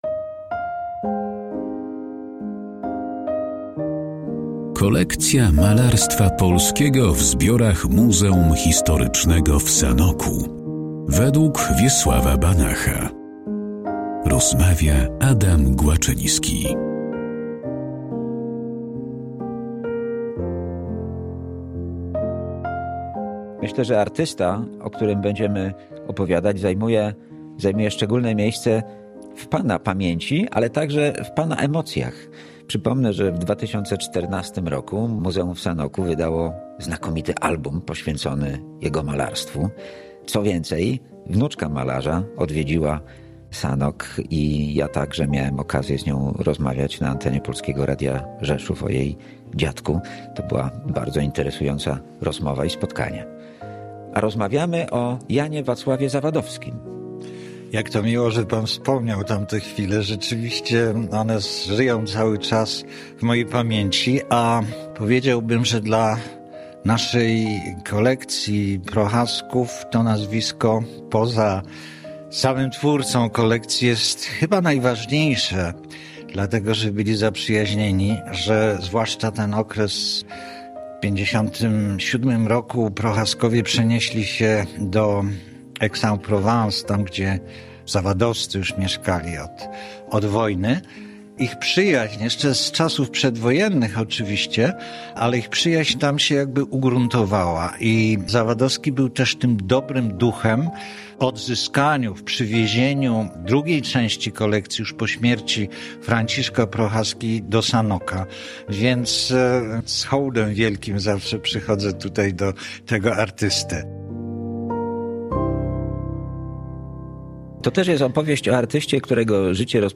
rozmawiają historyk sztuki